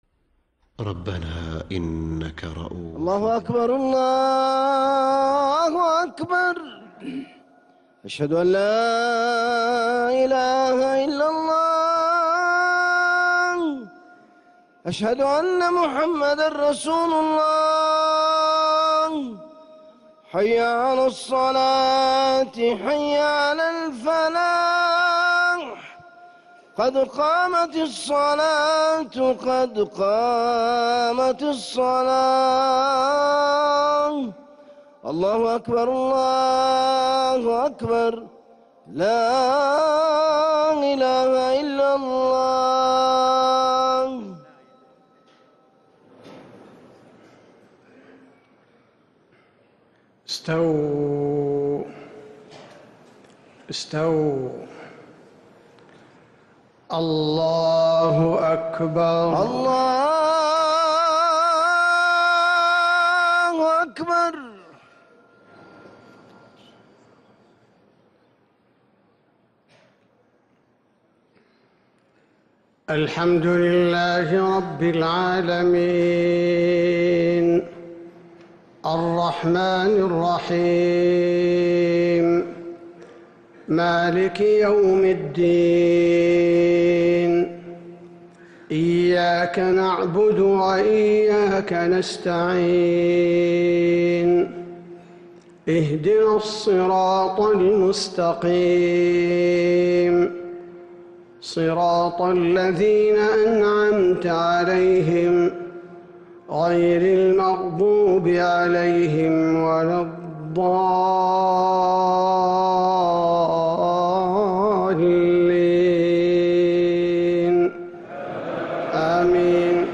Haramain Salaah Recordings: Madeenah Maghrib - 07th February 2026